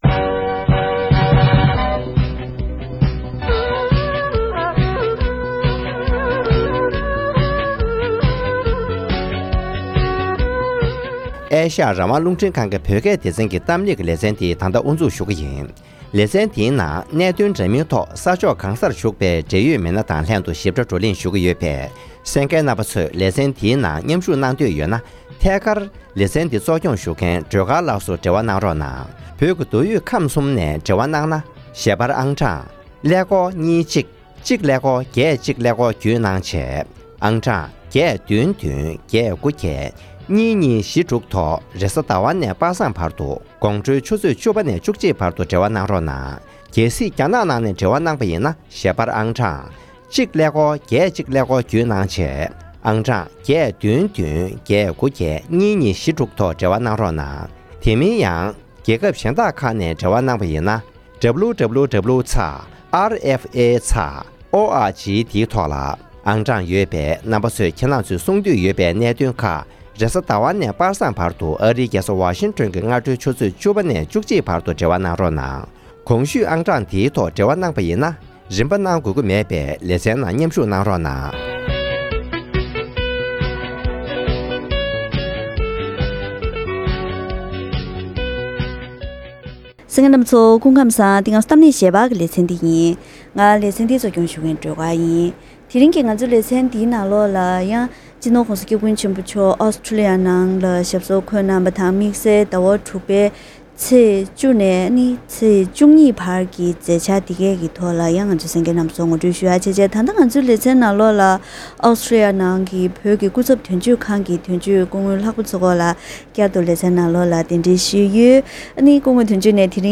༄༅། །ཐེངས་འདིའི་གཏམ་གླེང་ཞལ་པར་ལེ་ཚན་ནང་སྤྱི་ནོར་༧གོང་ས་༧སྐྱབས་མགོན་ཆེན་པོ་མཆོག་ནས་ཨོ་སི་ཁྲོ་ལི་ཡའི་ནང་འབྲེལ་ཡོད་སློབ་གསོ་རིག་པ་བ་དང་སེམས་ཁམས་རིག་པ་བ་སོགས་ཀྱི་མི་སྣ་དང་ལྷན་ཡ་རབས་སྤྱོད་བཟང་གི་སློབ་གསོ་གནང་ཕྱོགས་དང་དེ་བཞིན་ཆོས་ལུགས་རིས་མེད་ཀྱི་བགྲོ་གླེང་གནང་བ་སོགས་པའི་མཛད་འཆར་ཁག་གི་ཐོག་སྐུ་ཚབ་དོན་གཅོད་དང་འདི་གའི་གསར་འགོད་པ་ལྷན་བཀའ་མོལ་ཞུས་ཏེ་འབྲེལ་ཡོད་མཛད་འཕྲིན་ཁག་ཞིབ་ཕྲ་ངོ་སྤྲོད་ཞུས་པ་ཞིག་གསན་རོགས་གནང་།